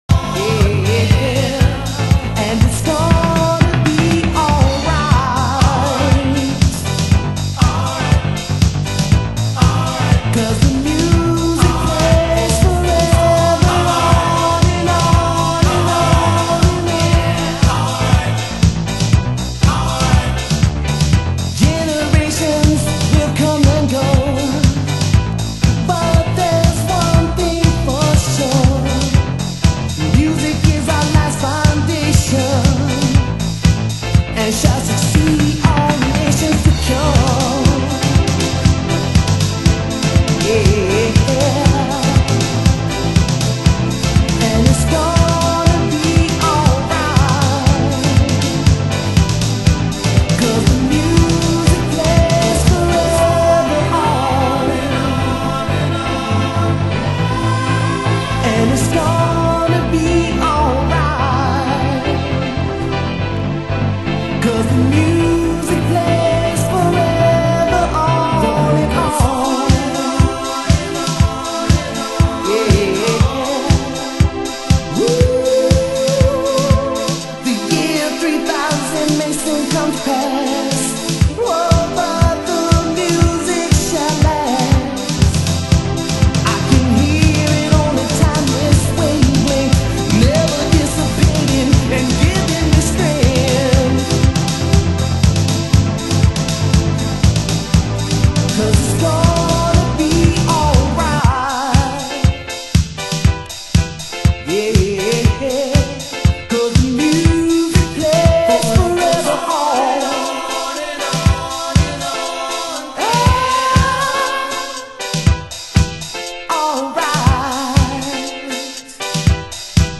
盤質：軽いスレ、小傷、少しチリパチノイズ有　　　ジャケ：スレ有